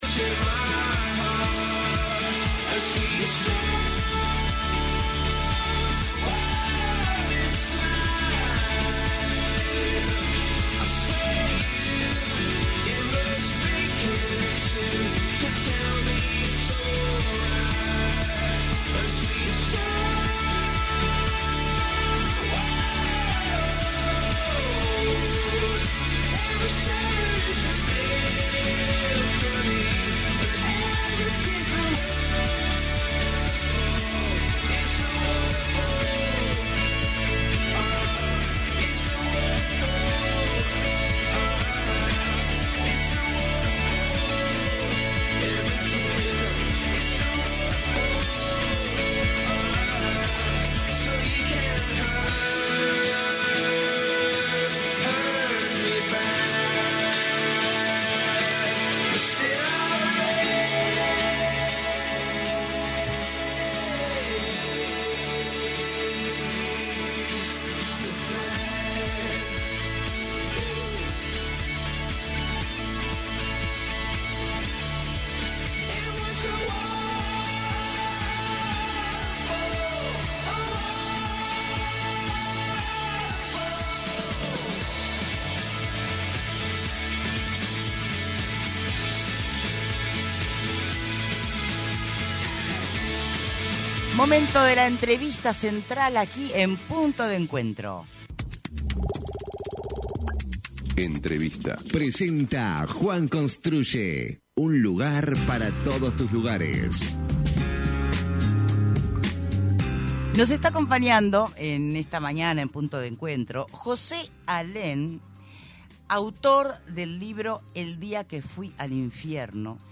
entrevista con Punto de Encuentro